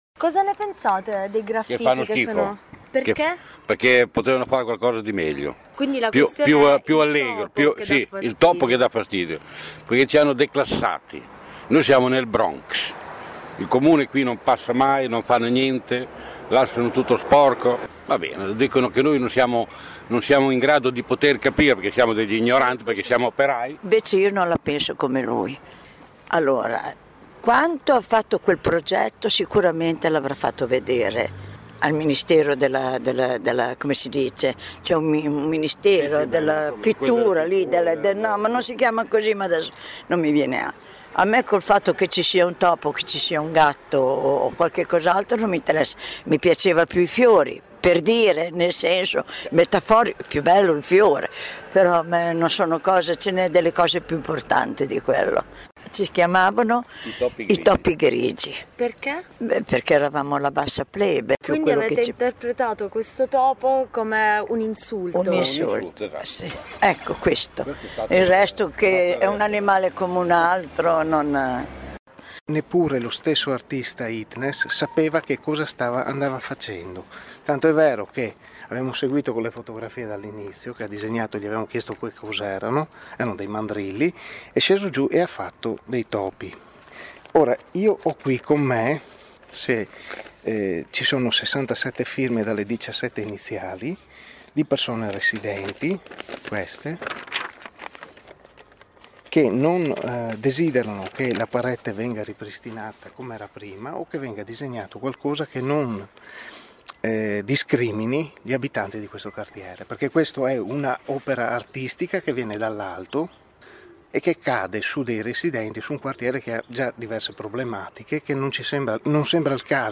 Ascolta le voci degli abitanti di via Pier De Crescenzi